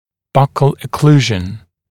[ˈbʌkl ə’kluːʒn][ˈбакл э’клу:жн]щёчная окклюзия, окклюзия в боковых отделах